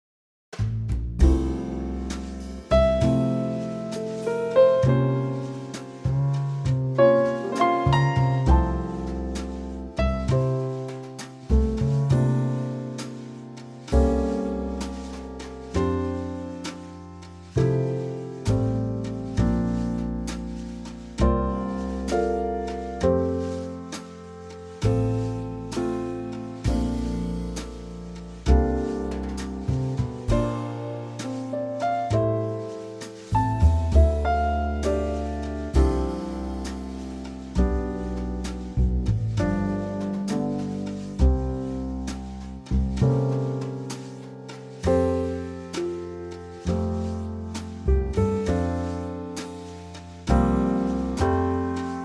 Unique Backing Tracks
key - F - vocal range - F to F
Gorgeous Trio arrangement
slowed down with oodles of lovely subtle musicality added.